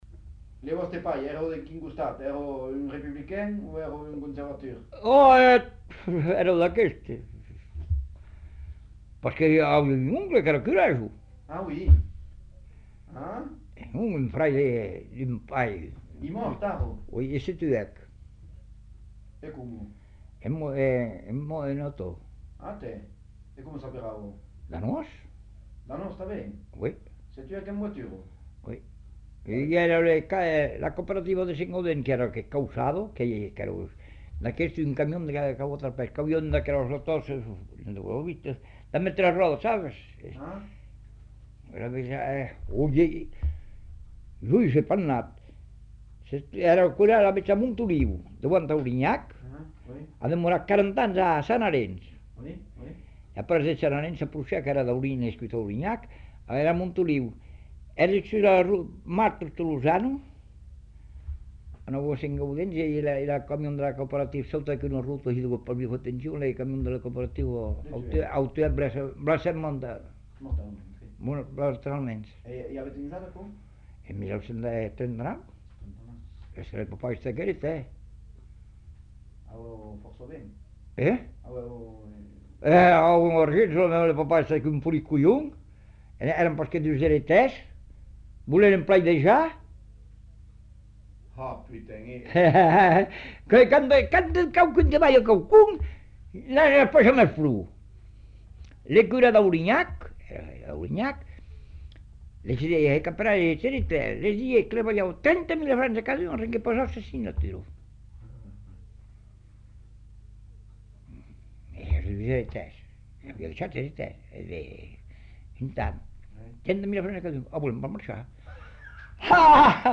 Aire culturelle : Savès
Genre : récit de vie